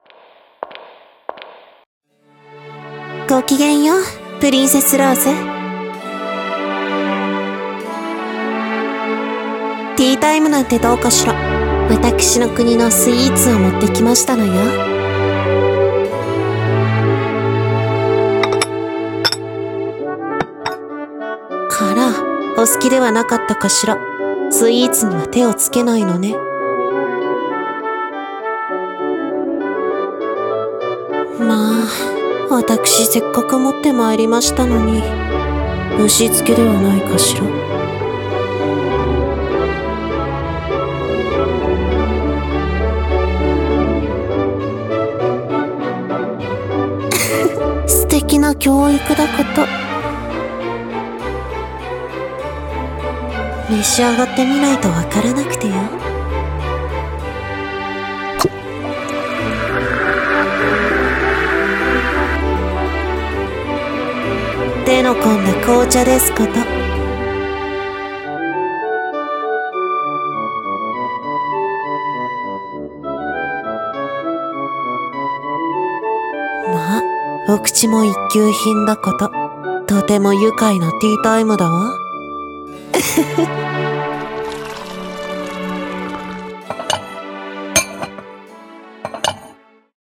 ティータイムに召しませ 【掛け合い 2人声劇】